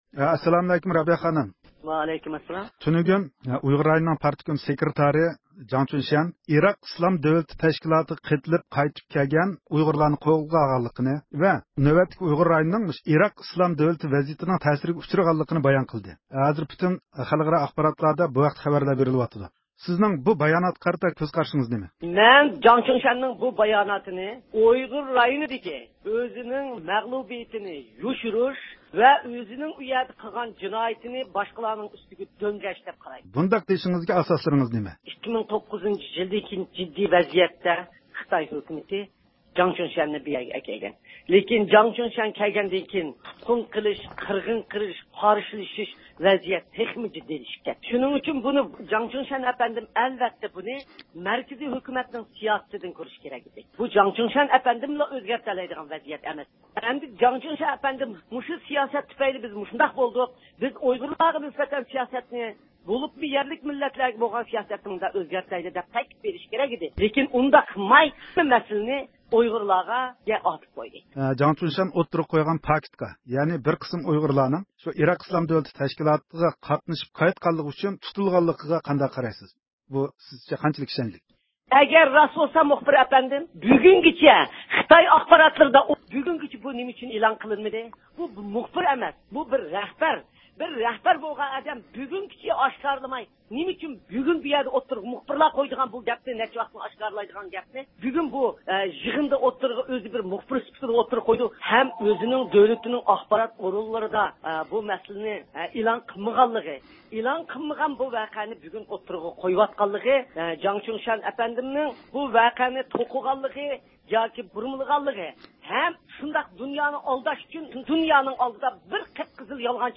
بۈگۈن ئۇيغۇر مىللىي ھەرىكىتى رەھبىرى رابىيە قادىر خانىم رادىئومىز زىيارىتىنى قوبۇل قىلىپ، جاڭ چۈشيەننىڭ سۆزلىرىگە رەددىيە بەردى.